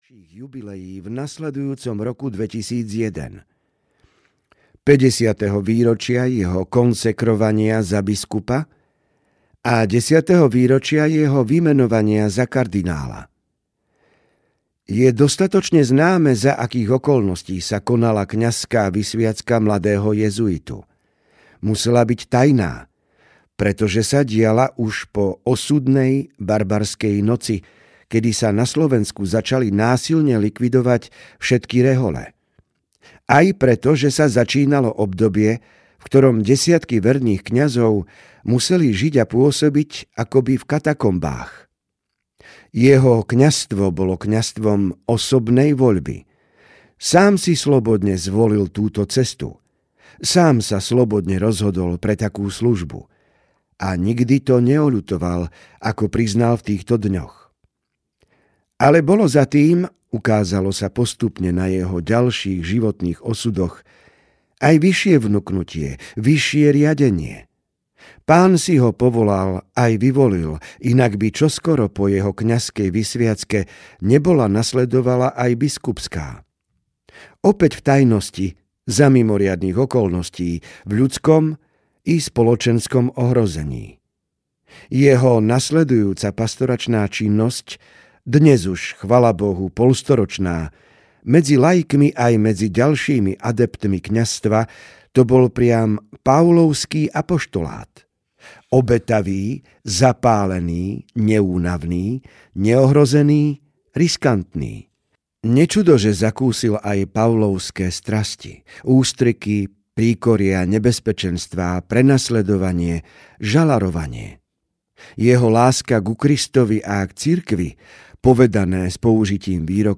Vznik evanjelií uprostred Cirkvi audiokniha
Ukázka z knihy